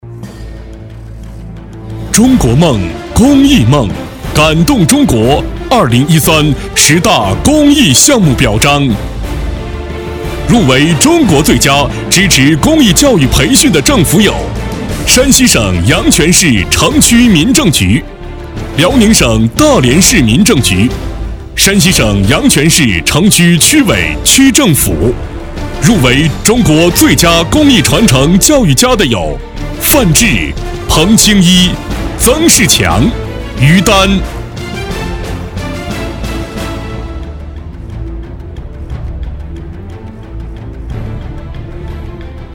颁奖
特 点：大气浑厚 稳重磁性 激情力度 成熟厚重